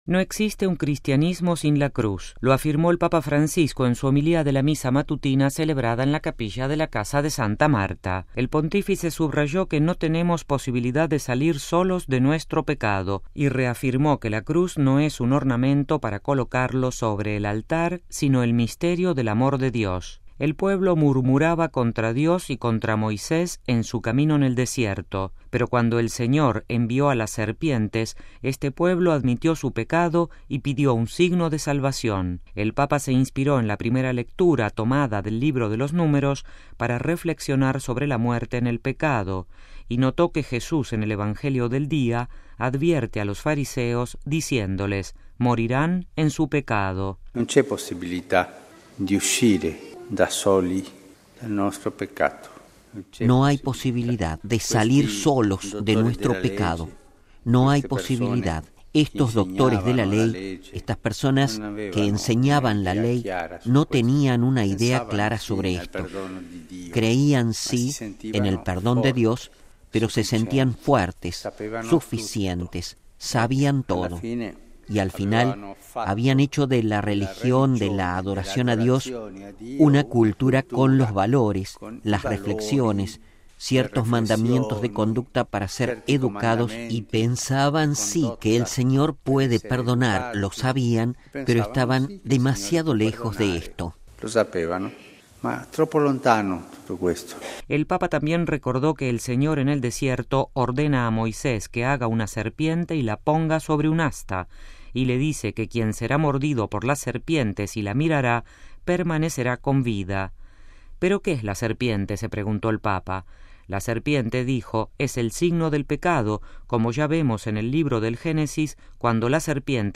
Lo afirmó el Papa Francisco en su homilía de la Misa matutina celebrada en la Capilla de la Casa de Santa Marta. El Pontífice subrayó que “no tenemos posibilidad de salir solos de nuestro pecado” y reafirmó que la Cruz no es ornamento para colocarlo sobre el altar, sino el misterio del amor de Dios.